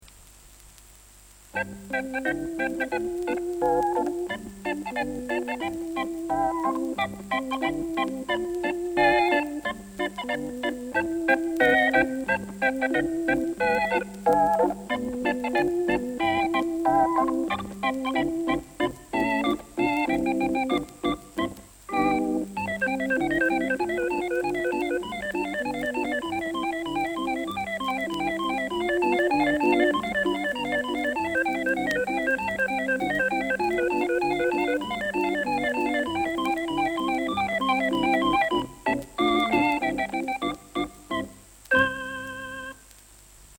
Liedjes op Hammond-orgel